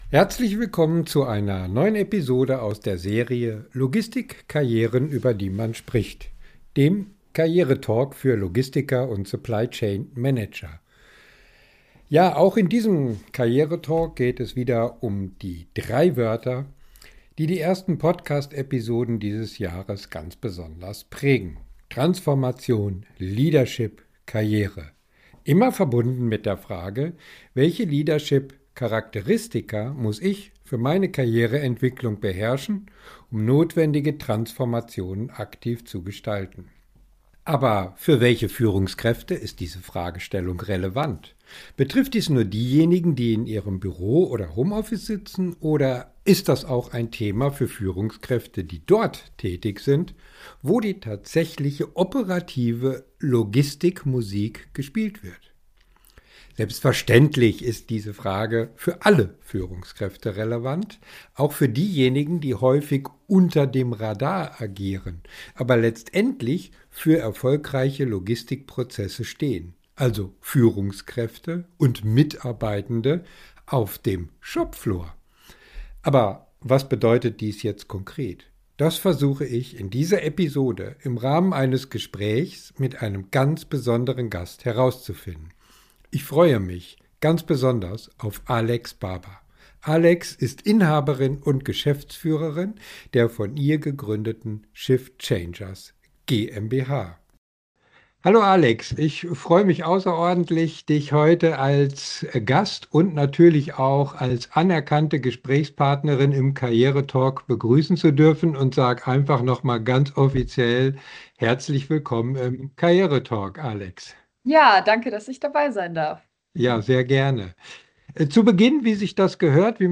Karriere TALK